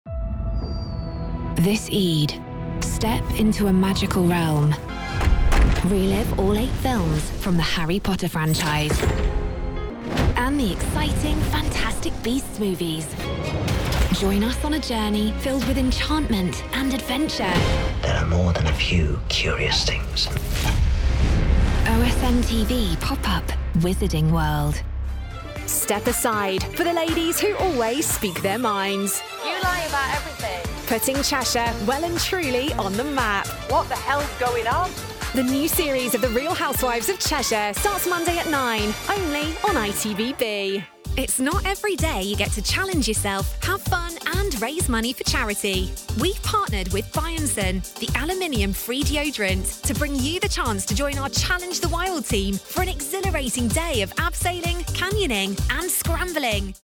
English (British)
Promos
Bright, warm, fresh, natural and professional are just some of the ways my voice has been described.